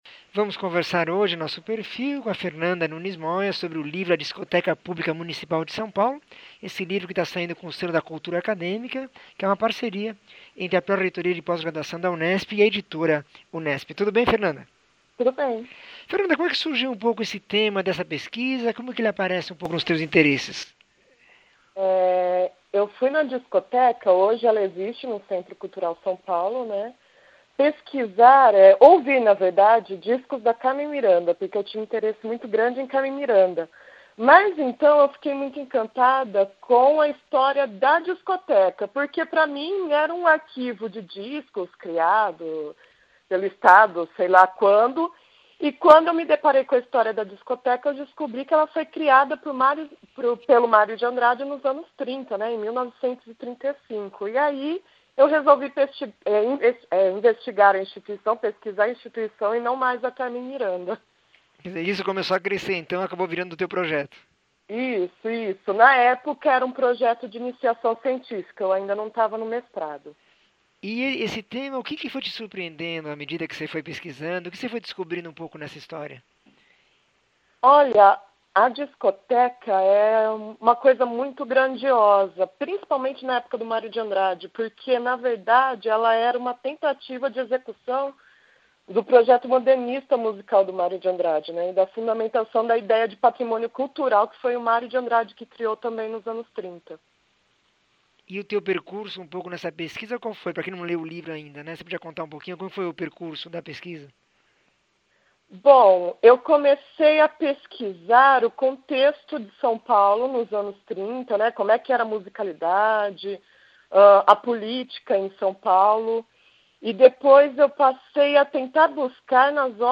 entrevista 1433